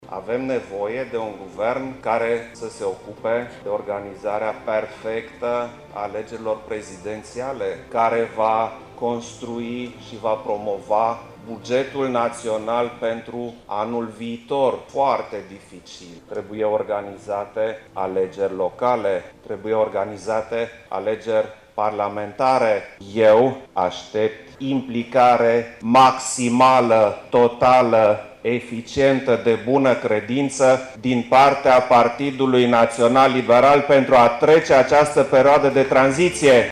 Tot astăzi, la Iaşi, preşedintele Klaus Iohannis, a declarat că după ce moţiunea de cenzură este adoptată, trebuie un guvern de tranziţie care să pregătească alegerile şi bugetul pe anul viitor.